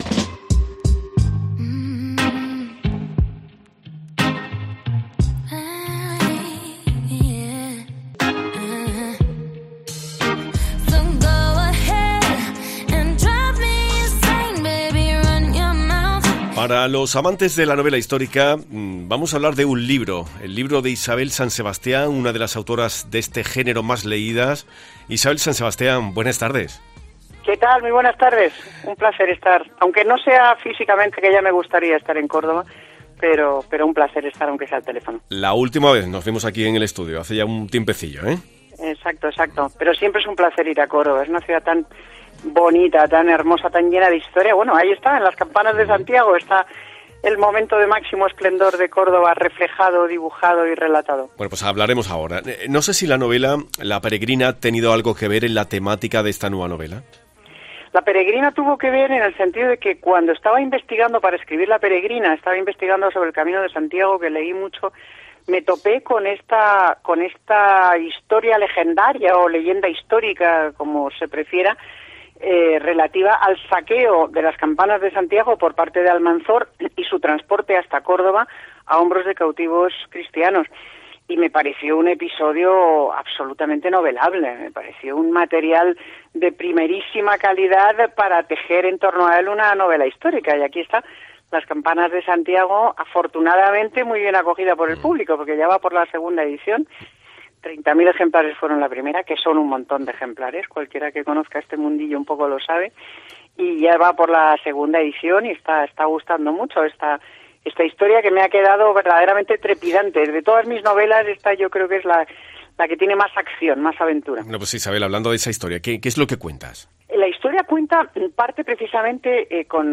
Isabel San Sebastián, la autora de novela histórica más leída en España, regresa con su proyecto más ambicioso, y hoy ha pasado por los micrófonos de COPE.